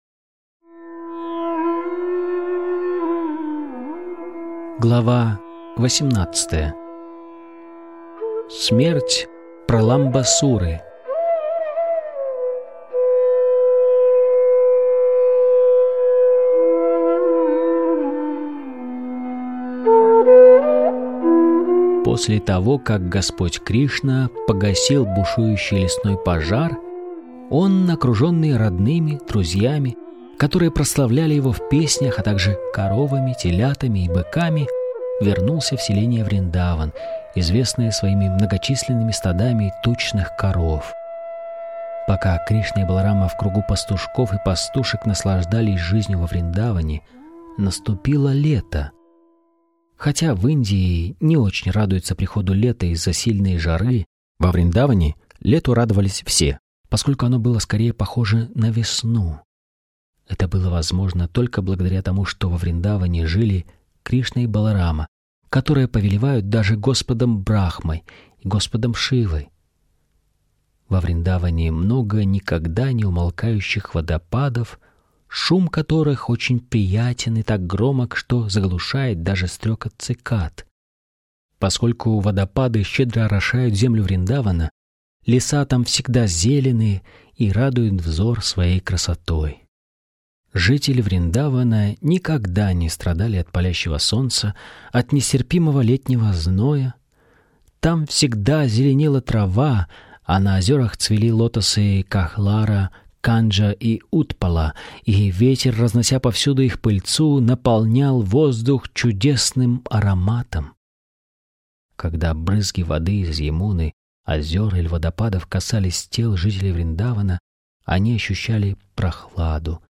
Аудиокнига: Кришна. Источник вечного наслаждения